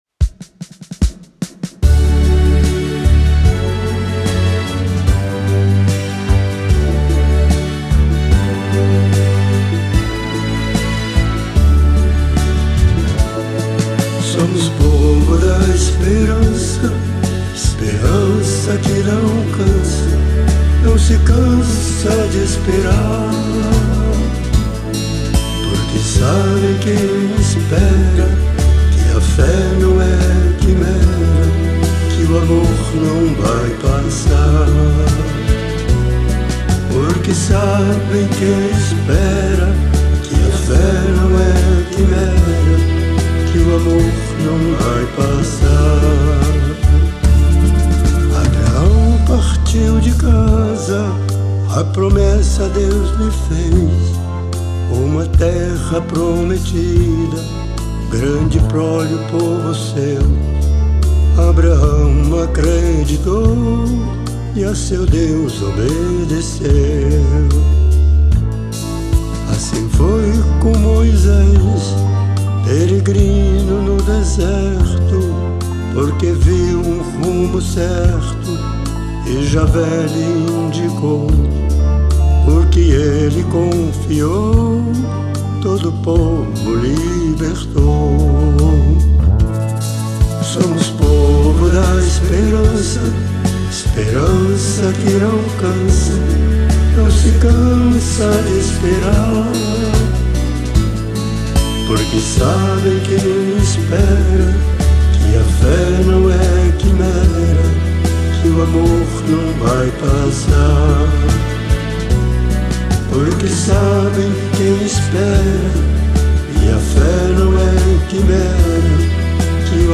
TOM: D